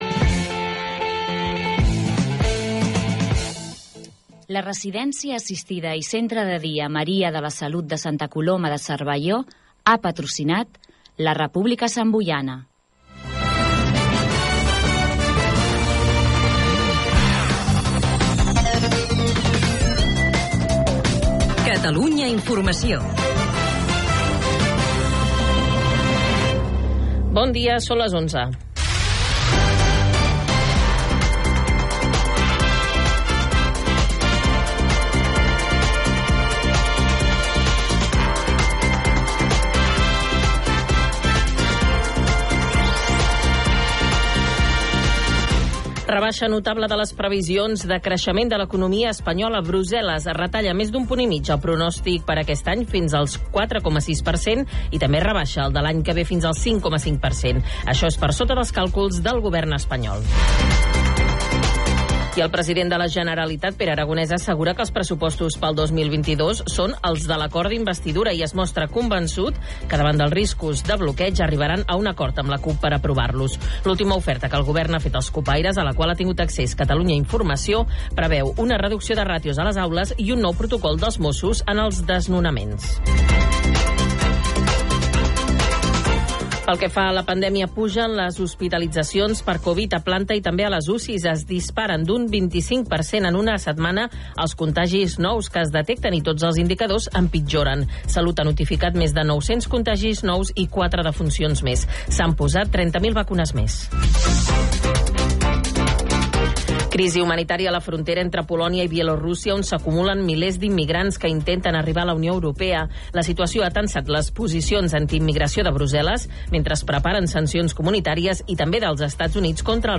Entrevista a Ràdio Sant Boi | Notícies | CNL Eramprunyà | Xarxa territorial | Consorci per a la Normalització Lingüística - CPNL